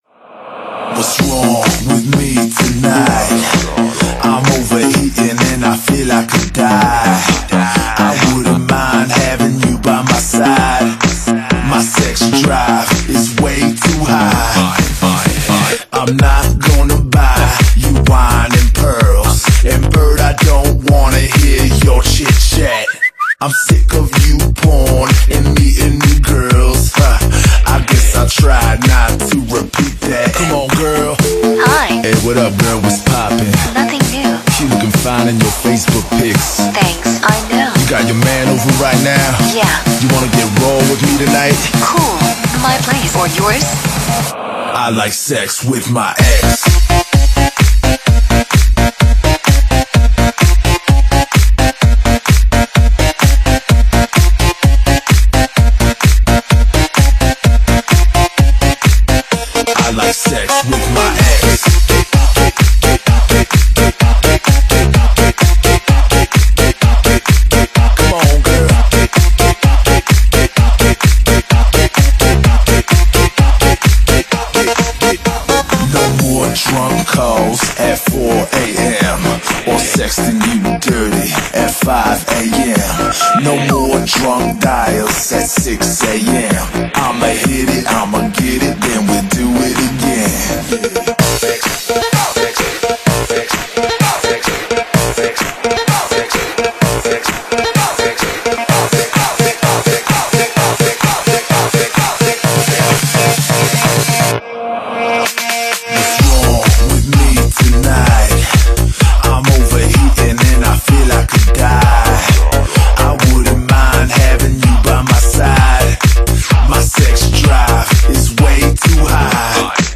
栏目：英文舞曲